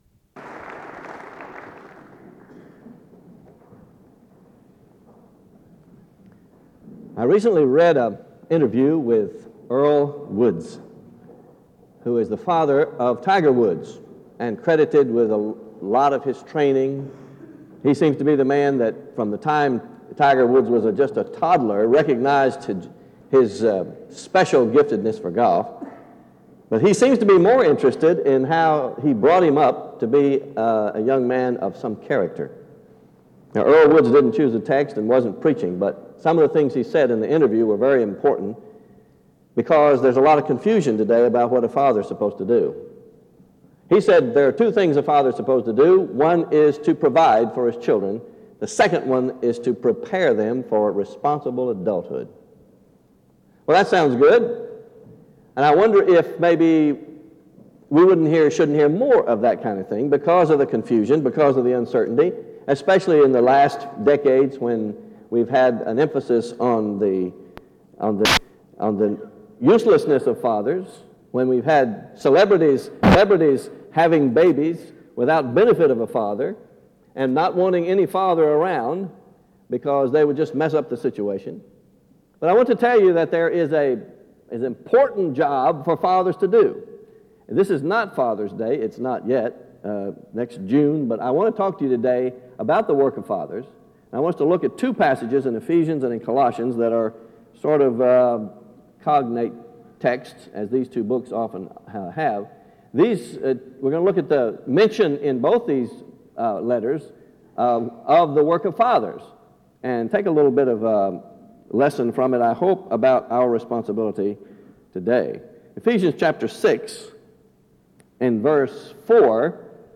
SEBTS Chapel and Special Event Recordings